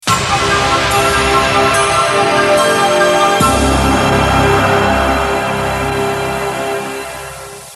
Incidental Music/Voice Over